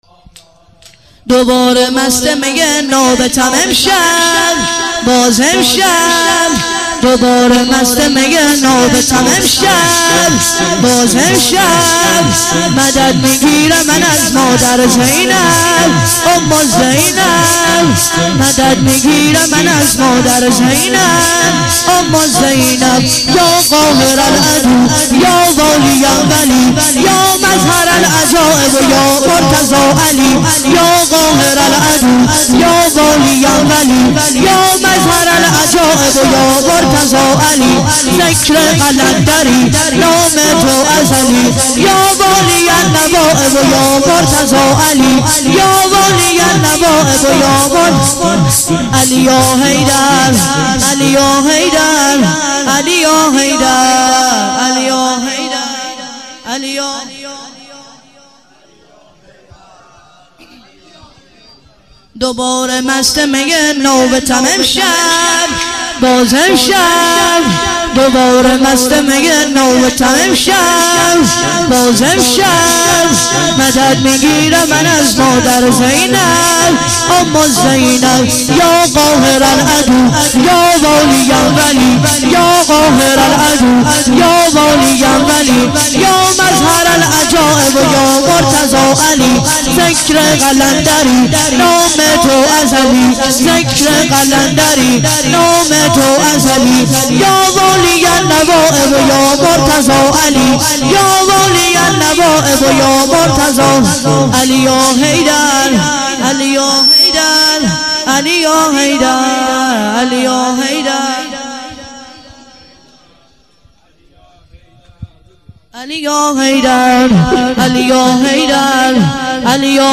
شور - دوباره مست می نابتم امشب